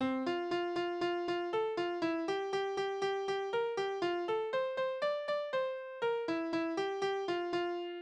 Kniereiterlieder: Hopp hopp über´n Graben
Tonart: F-Dur
Taktart: 4/8
Tonumfang: große None
Besetzung: vokal